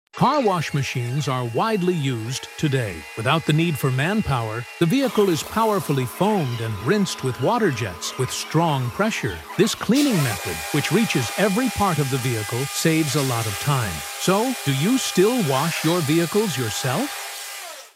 This rapid touchless car wash sound effects free download